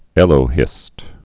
(ĕlō-hĭst, ə-lō-)